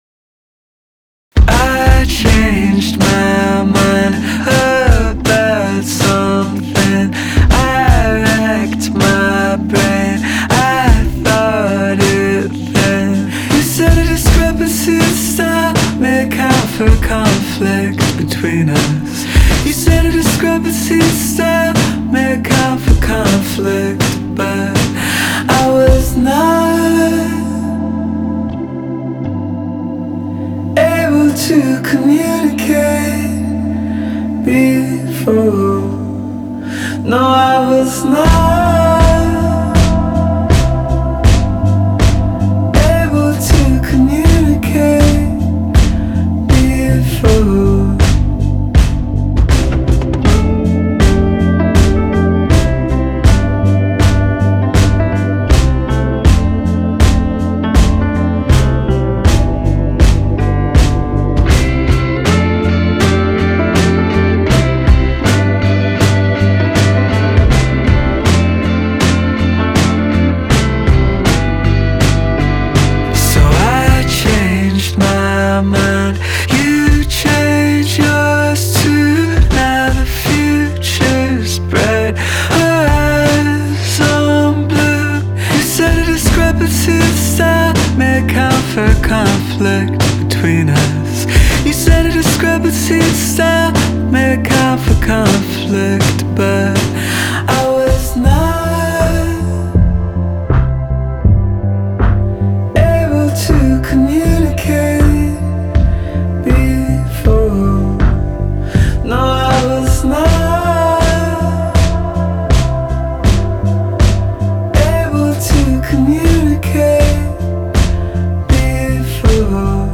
Genre : Alternative, Pop